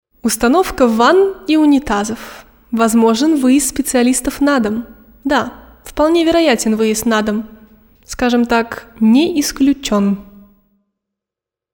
Молодой, теплый и солнечный женский голос. Неравнодушная подача и глубокое понимание материала.
Рекламные интонации на примере шуточных объявлений: